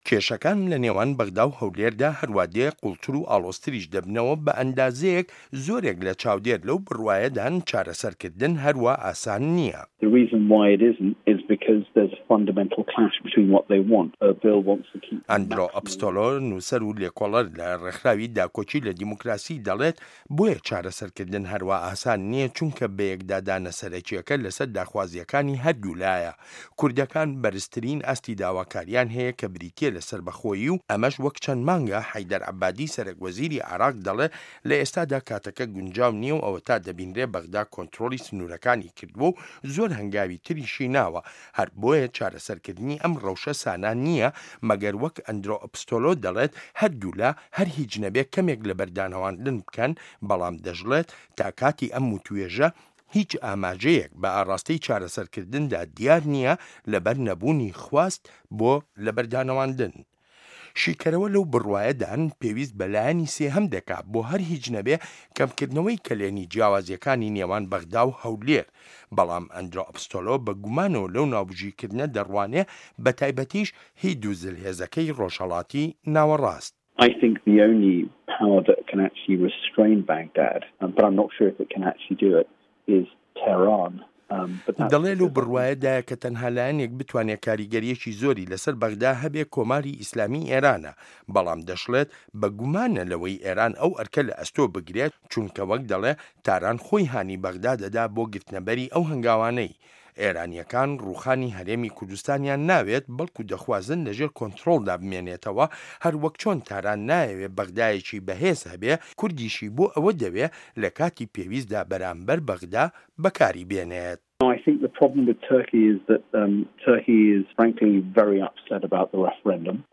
ڕاپۆرت